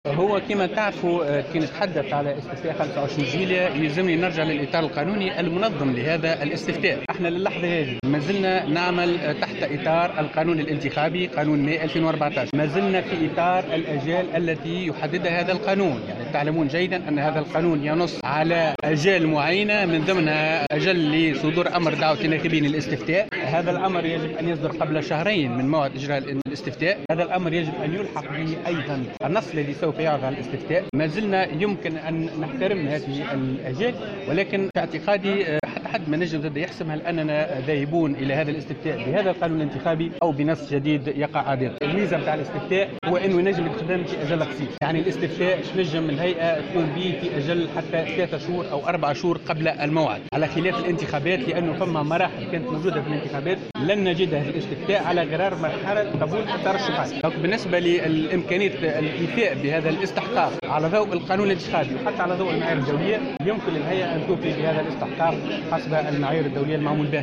وأضاف في تصريح اليوم لمراسلة "الجوهرة أف أم" أنه من الممكن إلى حدّ الآن احترام هذه الآجال، لكنه أشار في المقابل إلى أنه لم يتم الحسم بعد في ما إذا كان سيتم الذهاب إلى هذا الاستفتاء بهذا القانون الانتخابي أو بنص جديد.